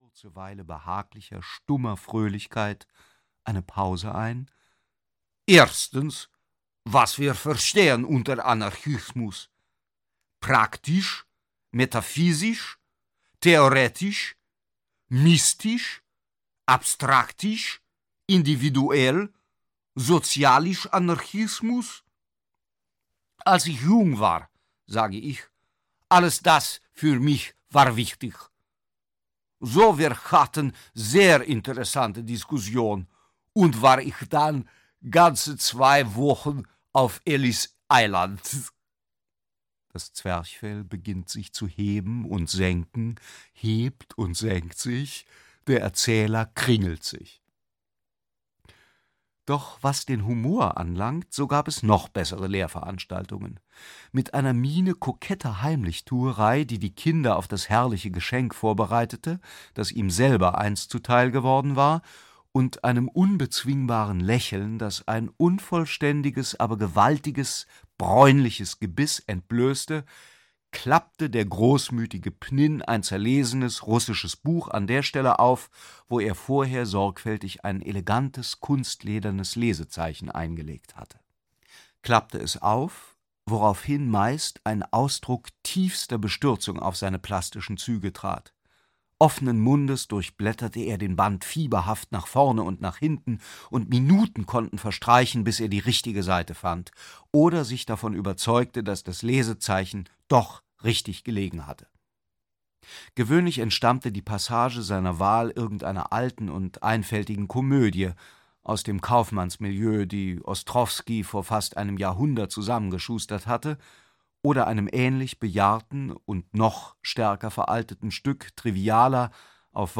Pnin - Vladimir Nabokov - Hörbuch